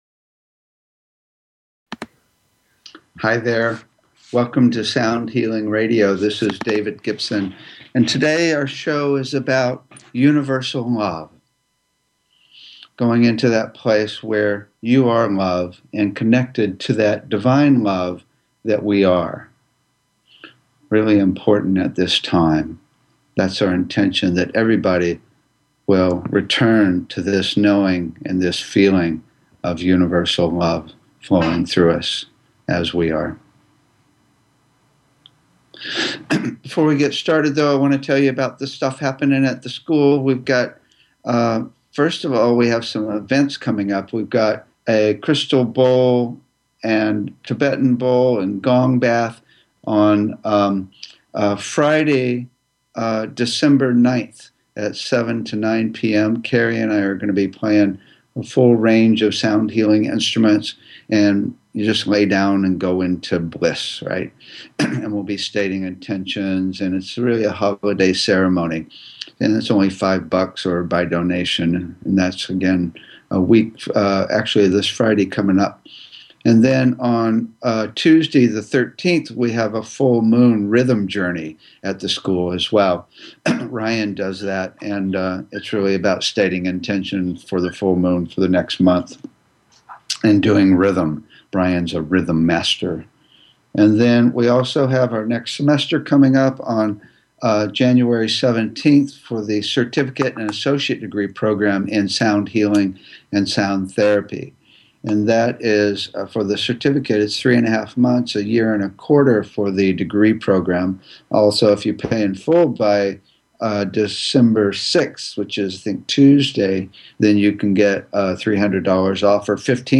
Talk Show Episode, Audio Podcast, Sound Healing and Sounds of Unconditional Love and more on , show guests , about Sounds of Unconditional Love,Unconditional Love Sounds, categorized as Health & Lifestyle,Sound Healing,Kids & Family,Music,Philosophy,Psychology,Self Help,Spiritual,Meditation